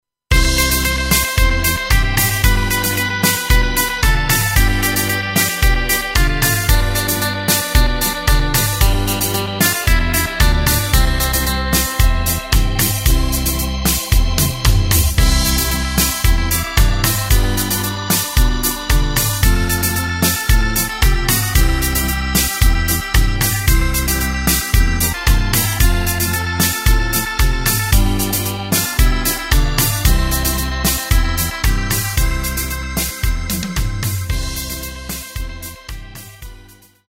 Takt:          4/4
Tempo:         113.00
Tonart:            C
Schlager/Oldie aus dem Jahr 1971!
Playback mp3 Demo